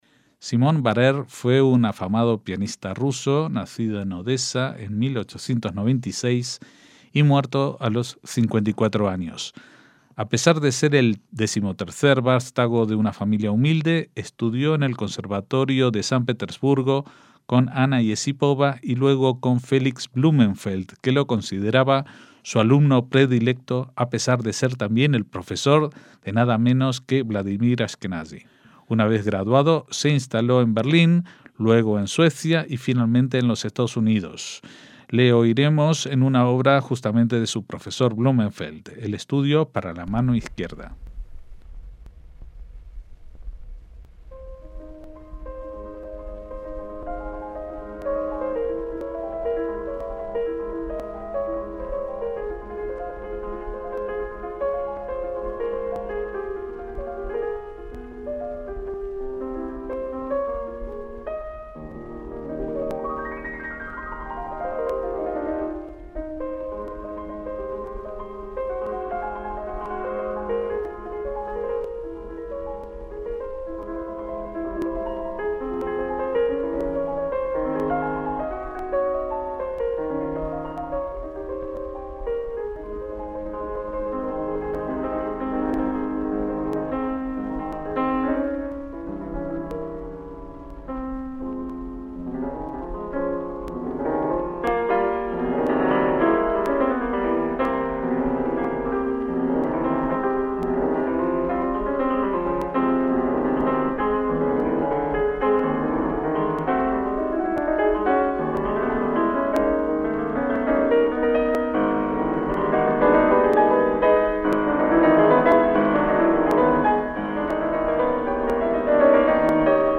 MÚSICA CLÁSICA - Joshua Rifkin (1944) es un director de orquesta, pianista y musicólogo estadounidense, profesor en la Universidad de Boston, y un destacado experto en la música del barroco, pero también conocido por jugar un papel central en el resurgimiento del ragtime en la década de 1970, a quien oiremos en varias piezas del Renacimiento y Barroco interpretadas por los London Brass Players.